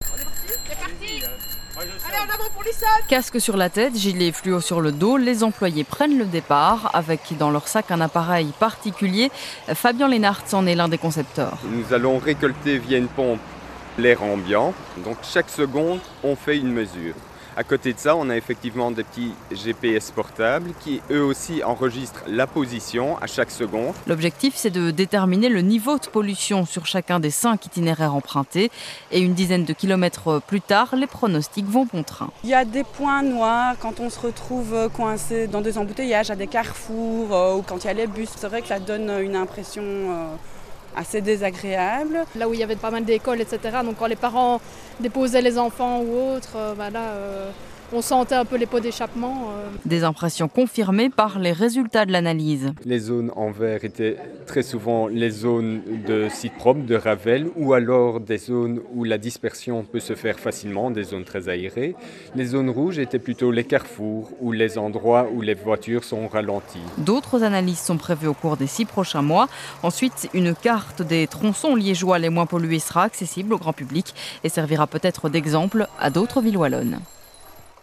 Reportages produits par la RTBF-Vivacité :
reportage 1